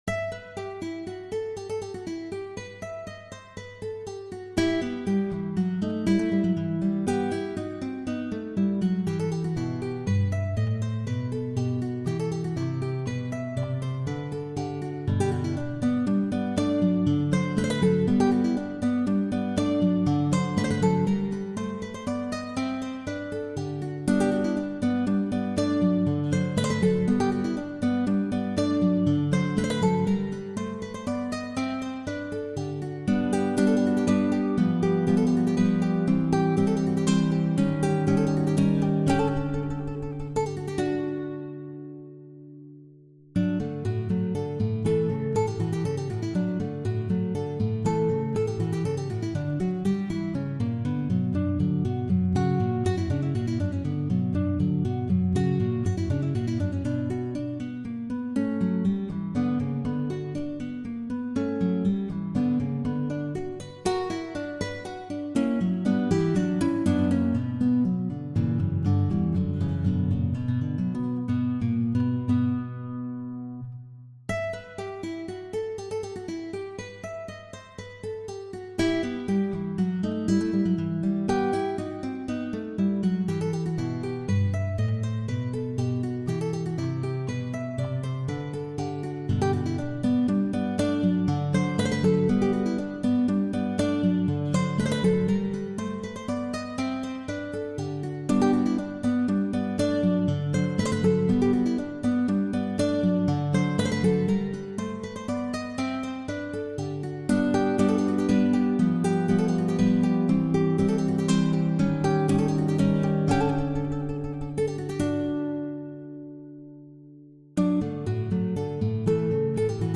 Sonate-en-mi-mineur.mp3